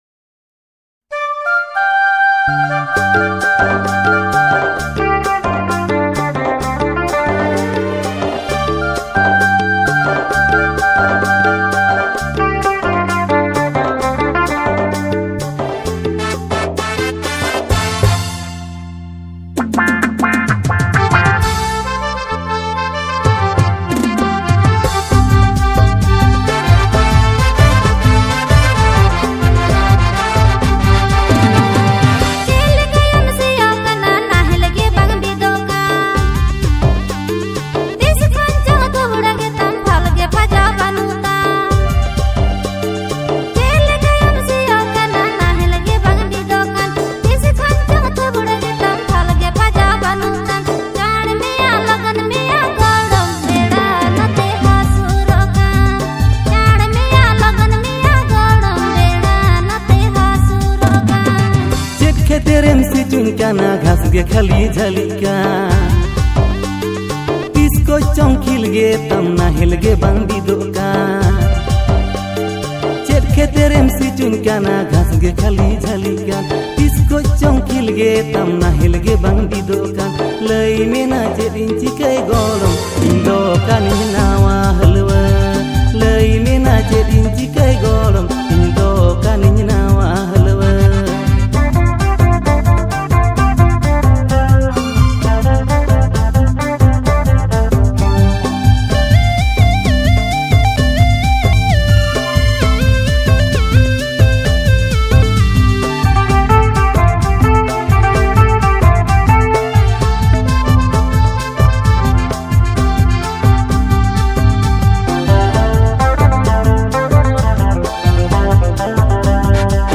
Santali song
• Male Artist